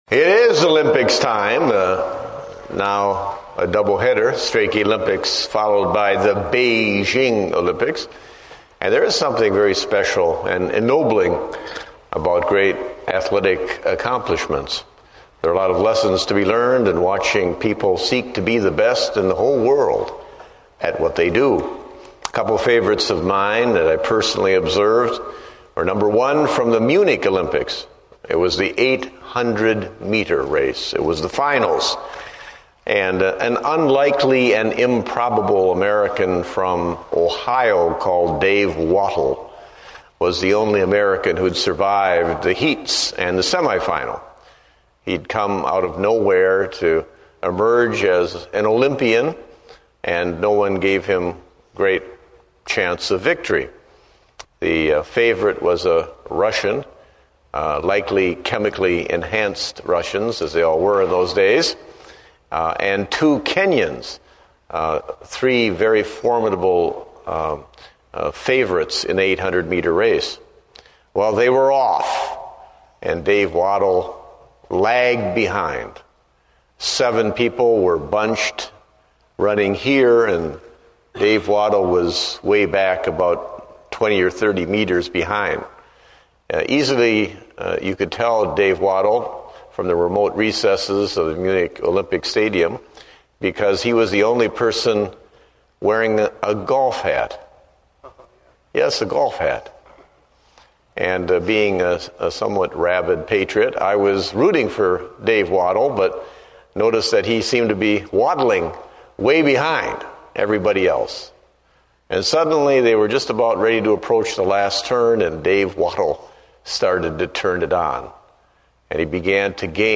Date: July 20, 2008 (Evening Service)